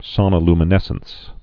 (sŏnə-lmə-nĕsəns)